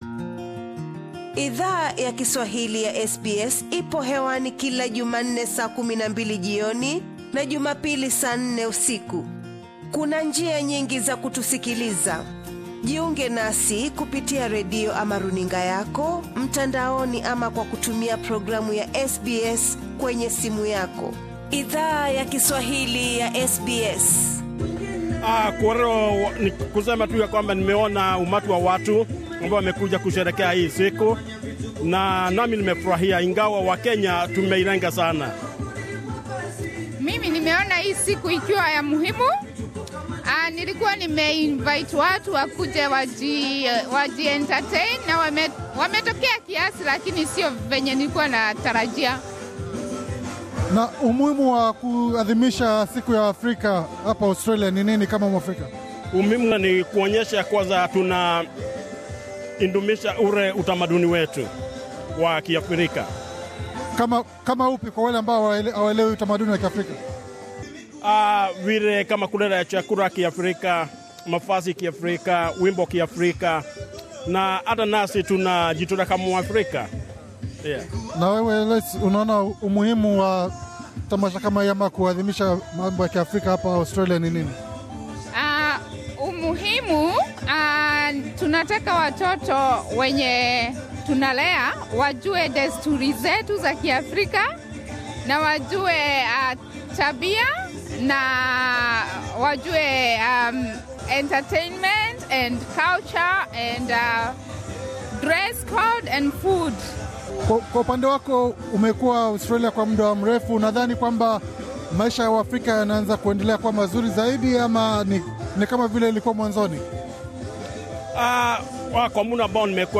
Siku ya Africa huadhimishwa katika nchi nyingi barani Afrika na sasa nchini Australia siku hiyo ime anza kuadhimishwa. SBS Swahili ilizungumza na baadhi ya waafrika walio hudhuria maadhimisho ya jiji la Blacktown pamoja na siku ya Afrika.